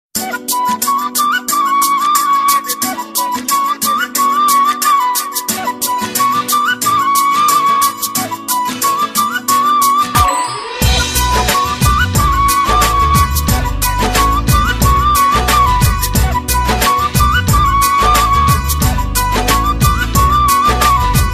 Bollywood
piano , desi ,